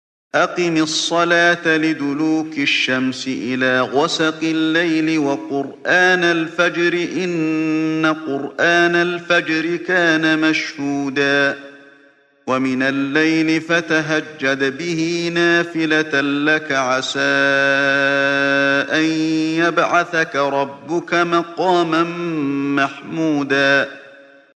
Чтение аятов 78-79 суры «аль-Исра» шейхом ’Али бин ’Абд ар-Рахманом аль-Хузейфи, да хранит его Аллах.